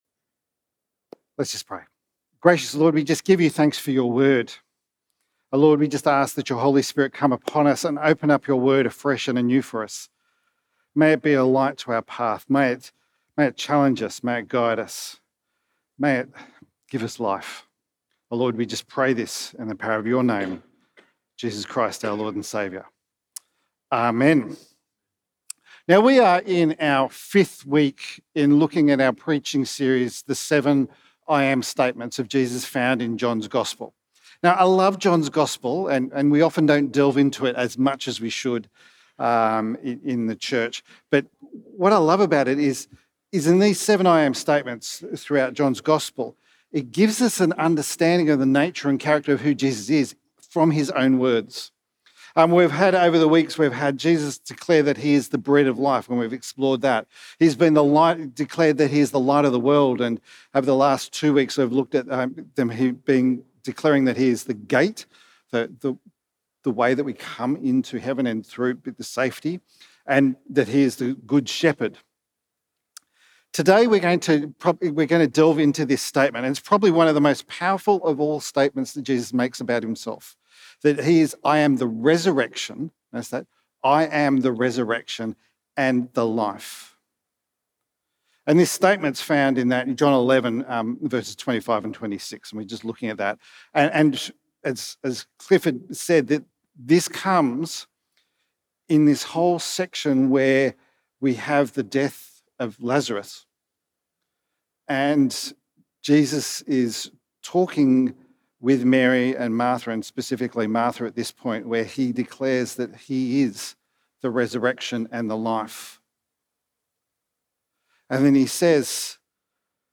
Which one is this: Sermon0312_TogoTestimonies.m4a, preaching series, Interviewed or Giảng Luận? preaching series